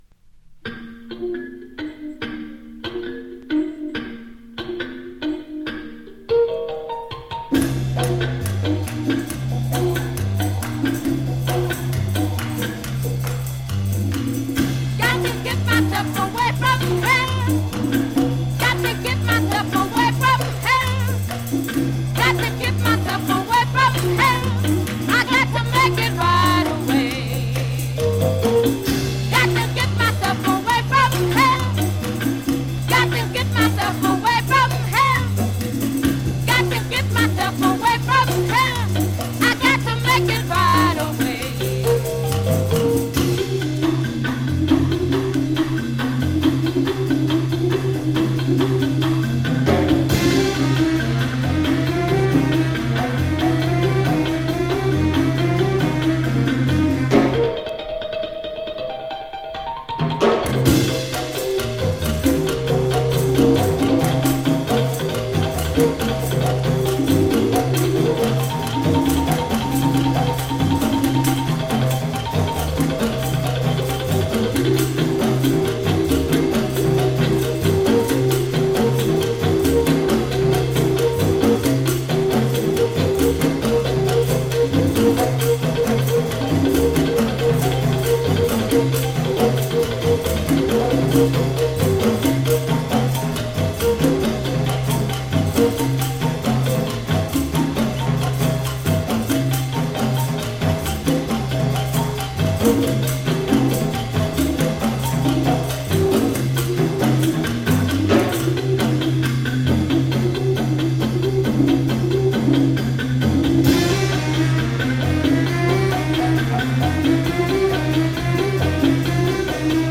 アフロセントリックで独創的な曲が並びます。